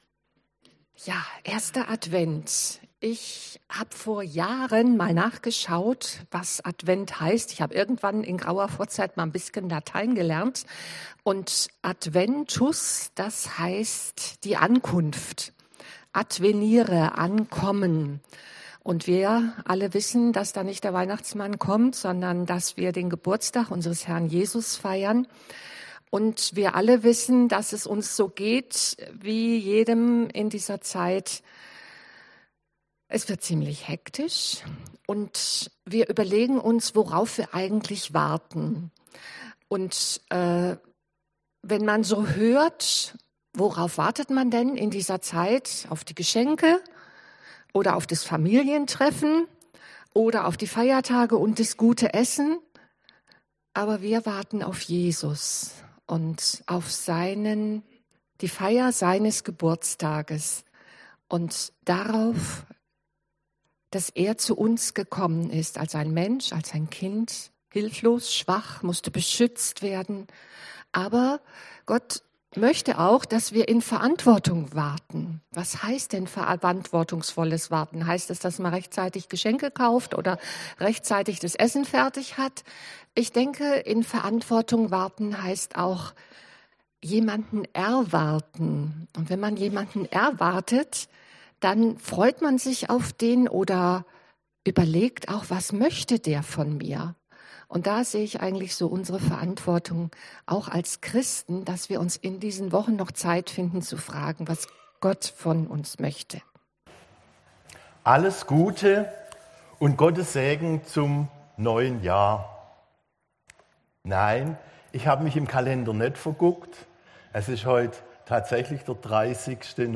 Passage: Röm 13 Dienstart: Gottesdienst Themen